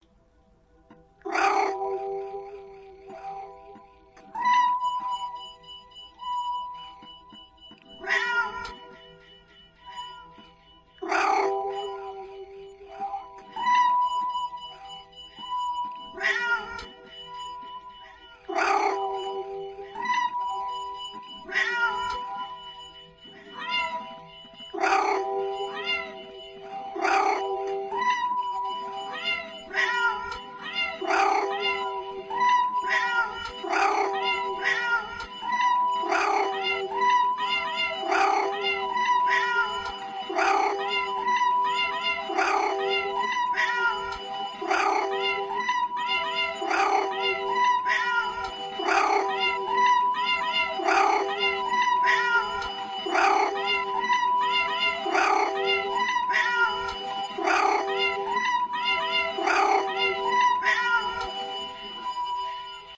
猫愤怒的嘶声
描述：愤怒的猫嘶声，。
标签： 烦乱 愤怒
声道立体声